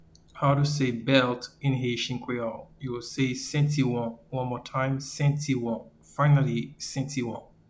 Pronunciation:
Belt-in-Haitian-Creole-Sentiwon-.mp3